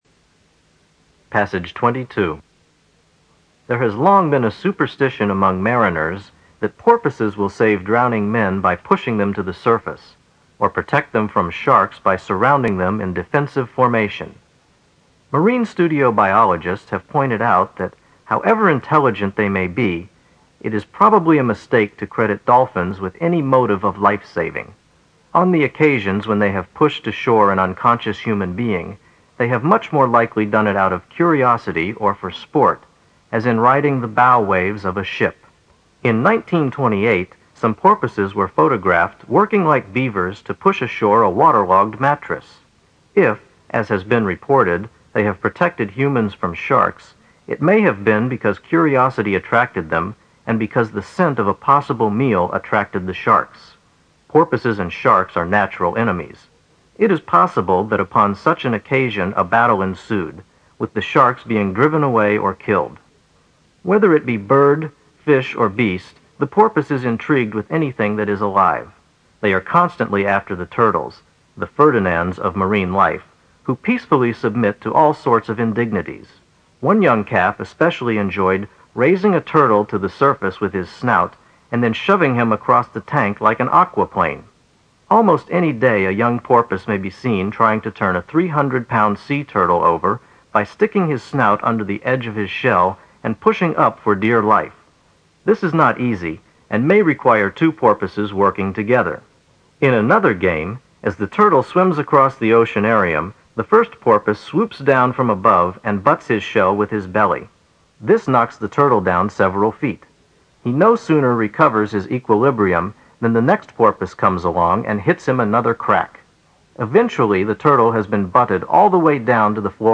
新概念英语85年上外美音版第四册 第22课 听力文件下载—在线英语听力室